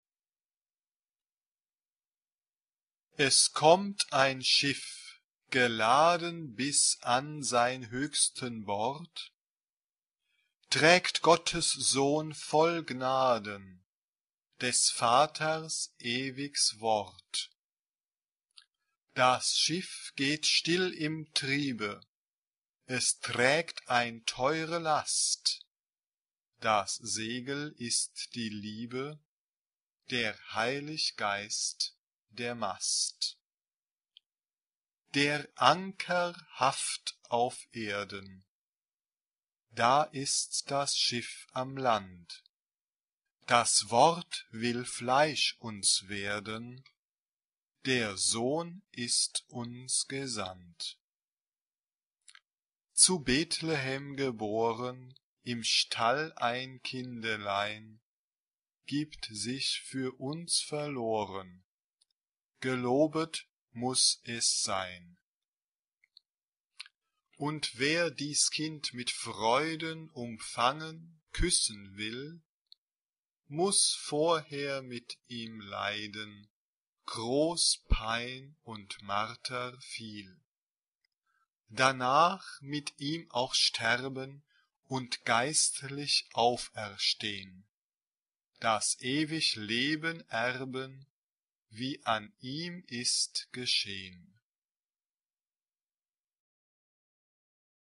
SAB (3 gemischter Chor Stimmen) ; Partitur.
Weihnachtslied.
Genre-Stil-Form: geistlich ; Weihnachtslied
Instrumente: Tasteninstrument (1)
Tonart(en): d-moll